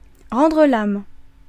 Prononciation
Île-de-France, France: IPA: [ʁɑ̃.dʁə l‿ɑm]